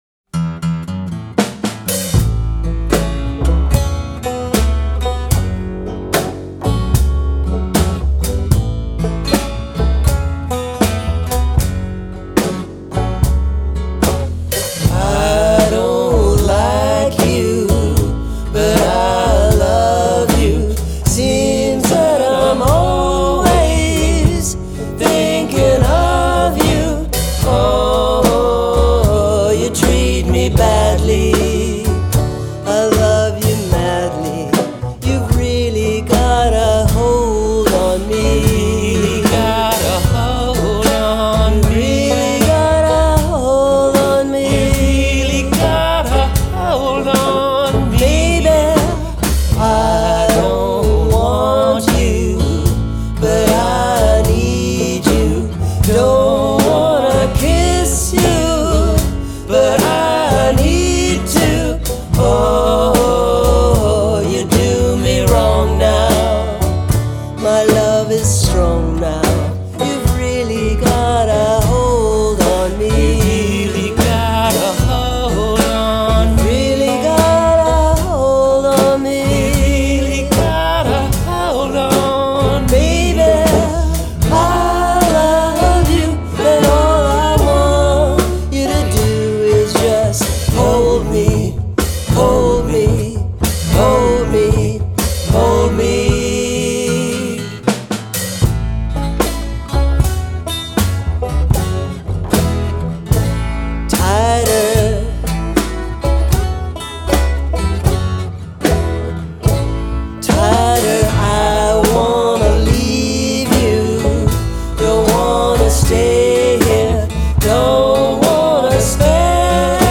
vocals/guitars/banjo
bass/drums